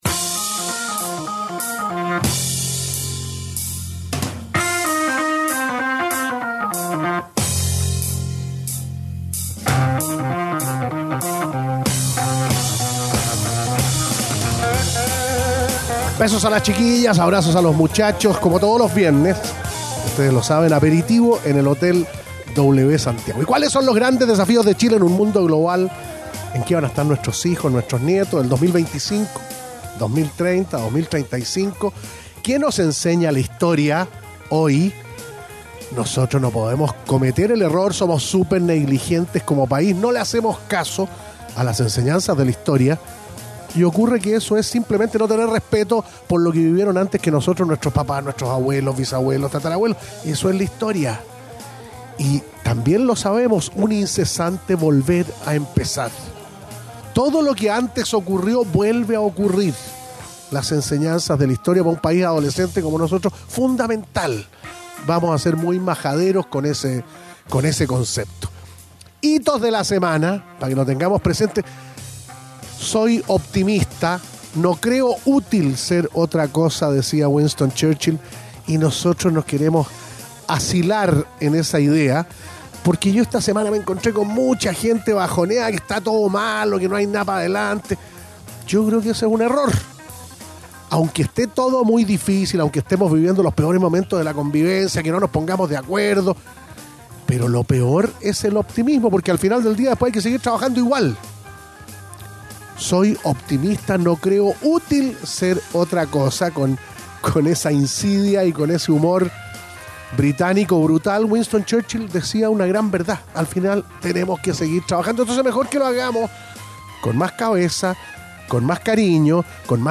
fue entrevistada